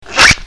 cartoon24.mp3